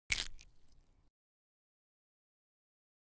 snd_hit2.ogg